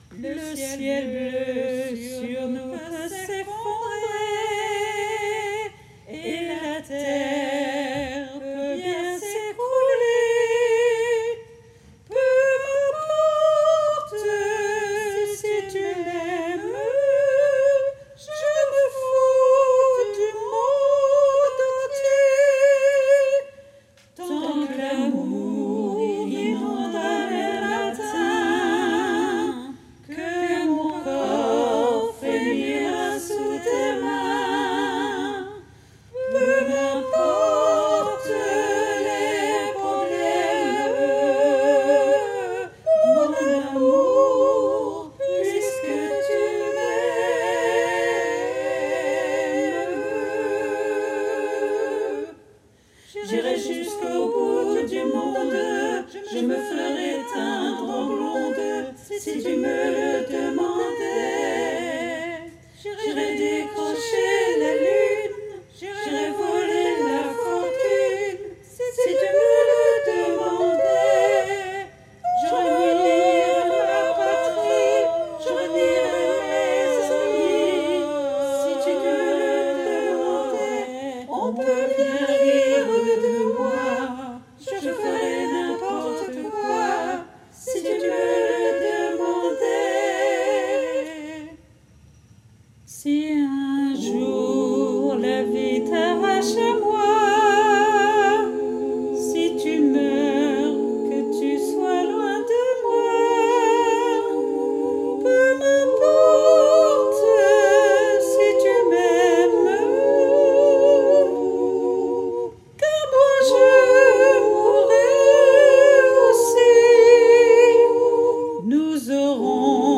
- Chanson pour chœur à 4 voix mixtes (SATB) + soliste
MP3 versions chantées
Tutti